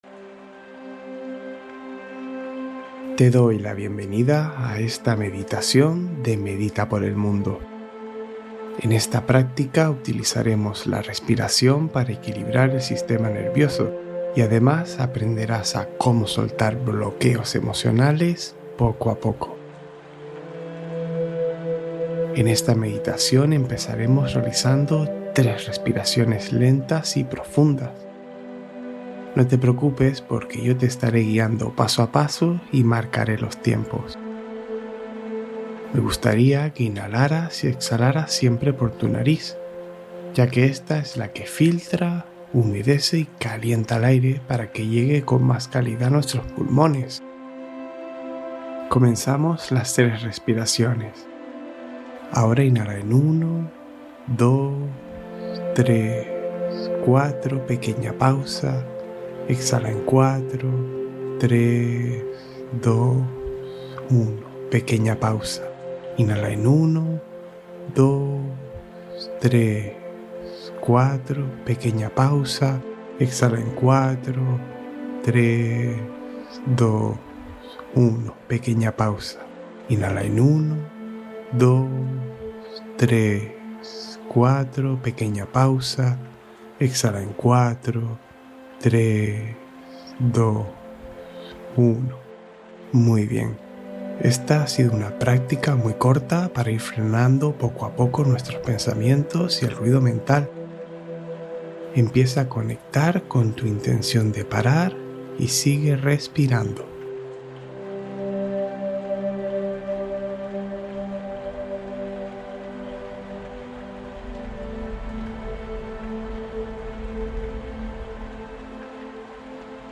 Prepara Tu Noche para el Descanso: Meditación de Relajación Profunda